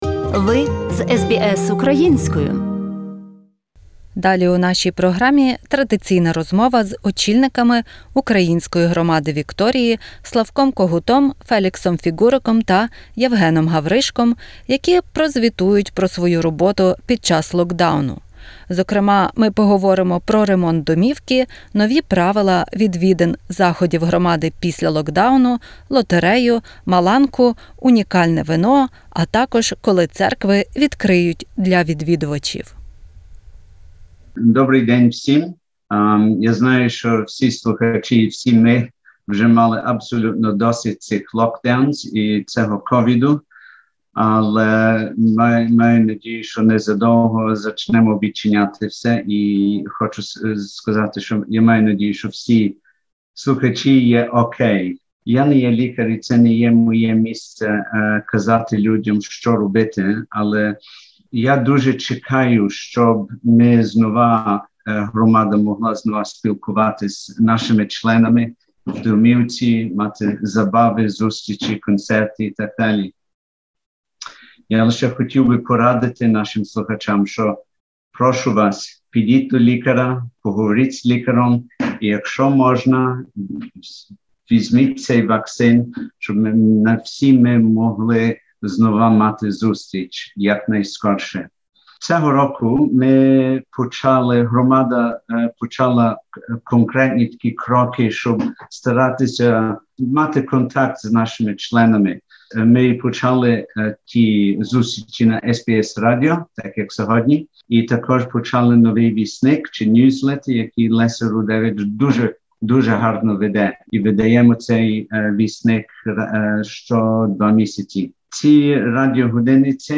Розмова з членами управи Української Громади Вікторії про діяльність спільноти під час локдауну та плани після нього